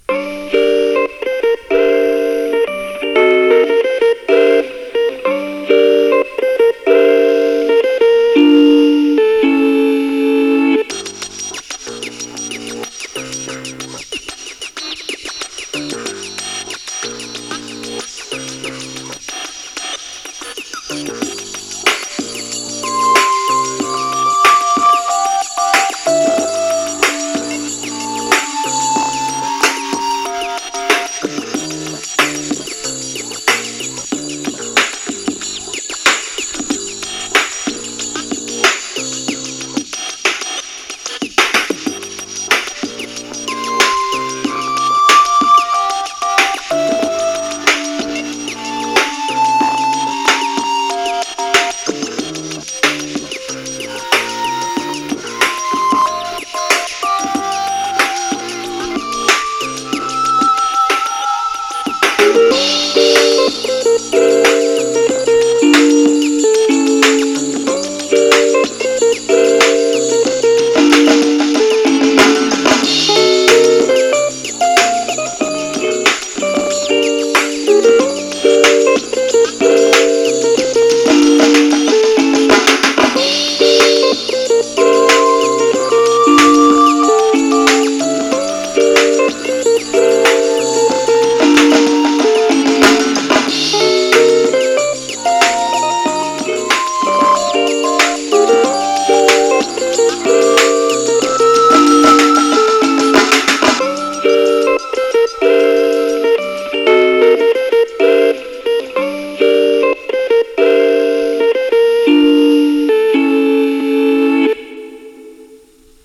Hip Hop Battle #42 Whatever The Weather.
• Kick and Snare are 808 emulations
• Sci-Fi Hats were my one synth, a stack of three ring-mod oscillators, with tweaking for open hats and chirps
Another music jam group I’m in had a “lo-fi winter” theme at the same time as this battle, so I had some additional tweaks post-battle, including recording the second version direct from boombox speakers with an SM58.